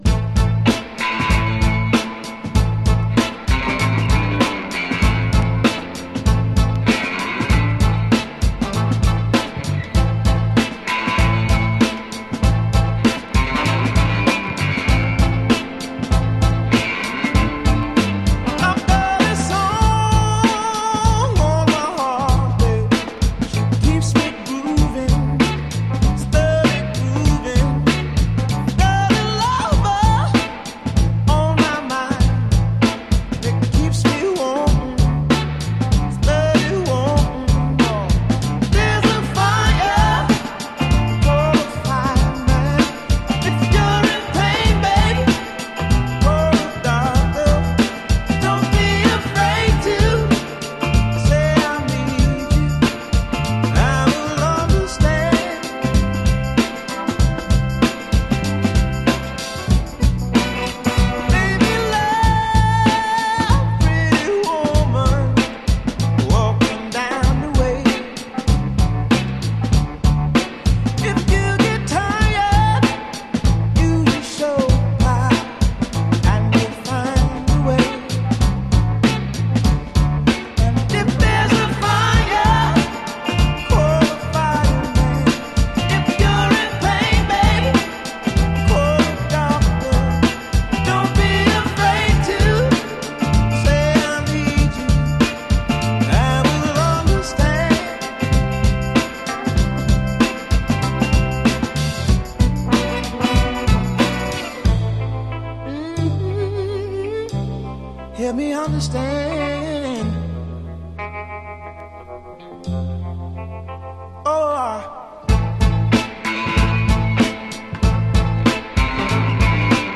Genre: Modern Soul